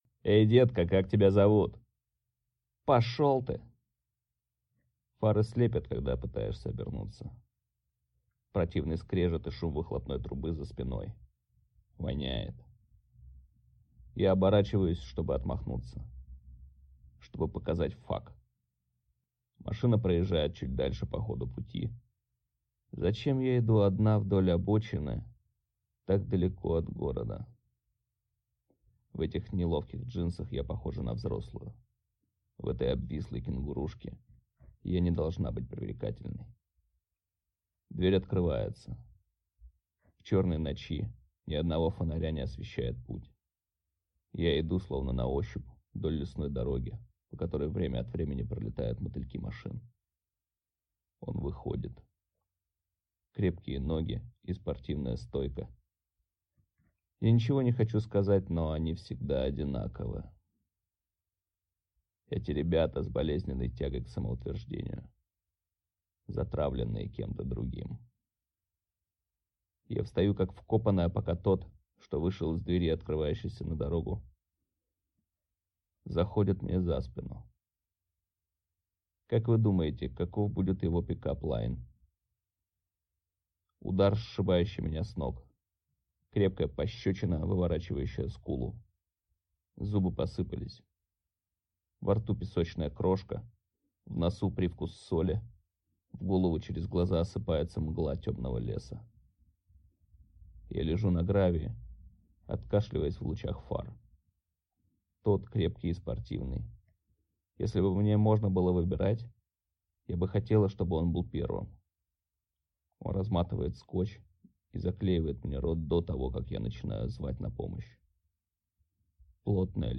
Аудиокнига Люси. Напряженная история о похищенном убийстве | Библиотека аудиокниг